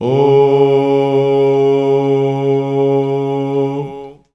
Index of /90_sSampleCDs/Voices_Of_Africa/LongNoteSustains
18_03_Ooo_Db.WAV